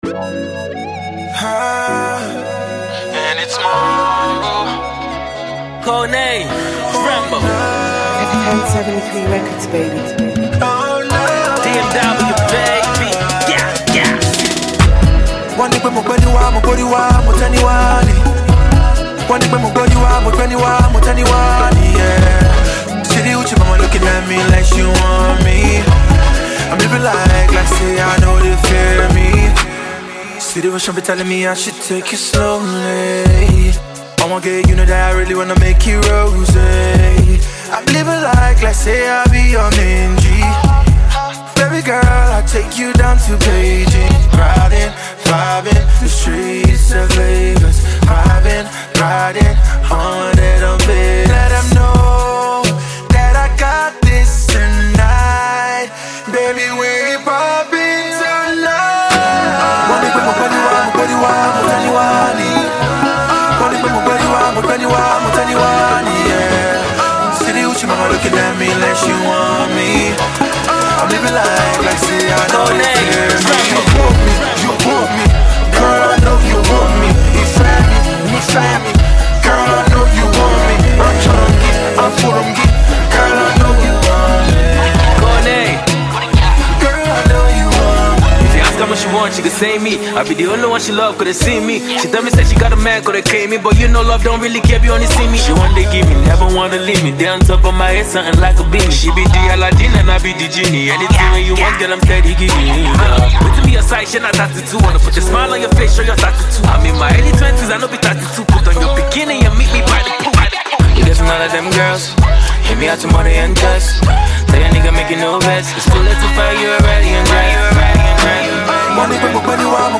budding rapper